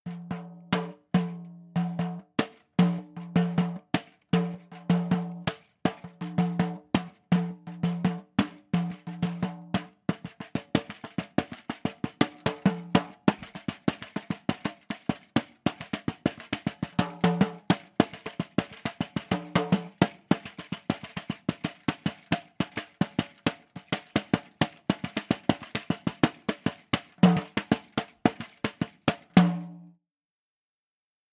samba pandeiro wood sound.wav
Original creative-commons licensed sounds for DJ's and music producers, recorded with high quality studio microphones.
samba_pandeiro_wood_sound_sjc.mp3